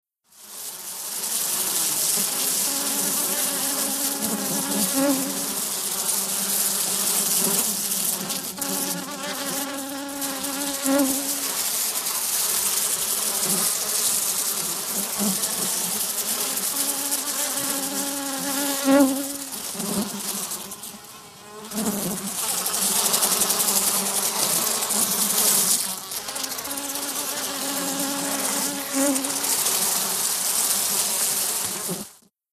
FliesCUSwarm PE660401
ANIMAL FLIES: EXT: Close up swarm of flies with intermittent bys.